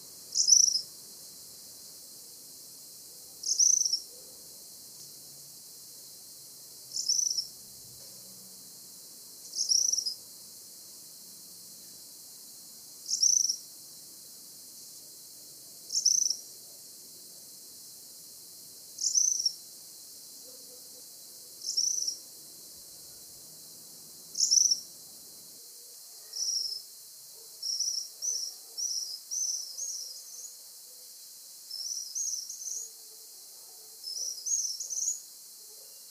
Tropical Kingbird (Tyrannus melancholicus)
Life Stage: Adult
Location or protected area: San Salvador de Jujuy
Condition: Wild
Certainty: Observed, Recorded vocal